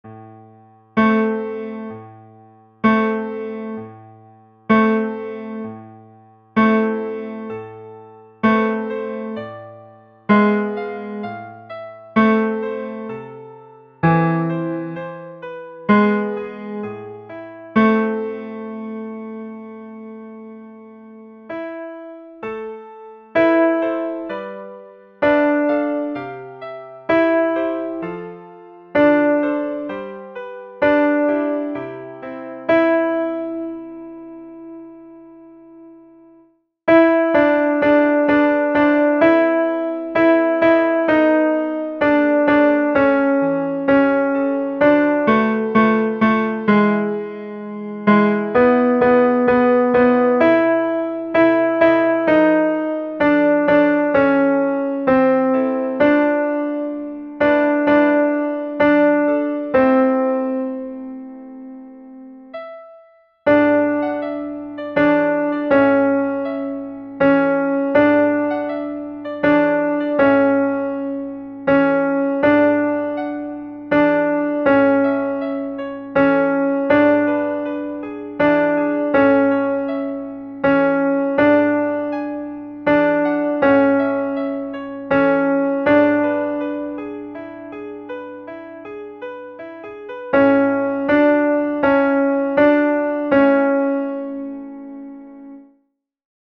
Ténors
chanson_de_solveig_tenors.mp3